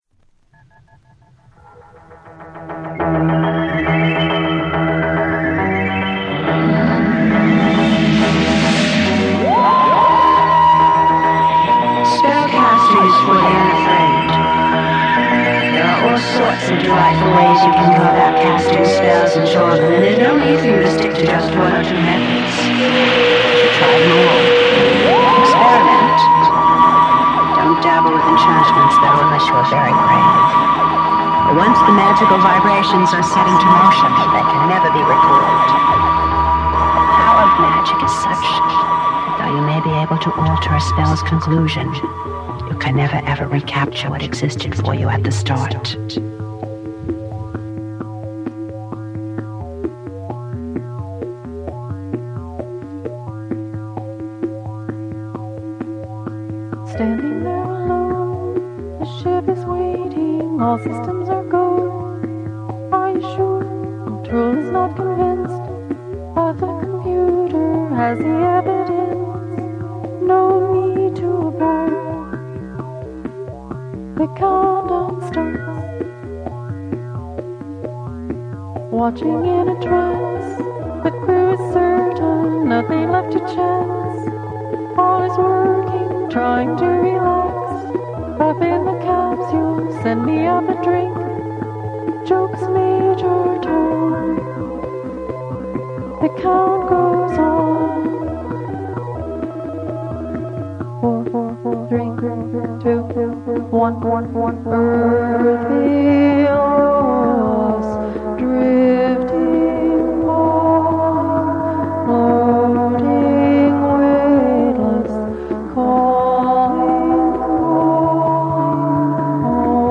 Ambient Electronic Experimental Folk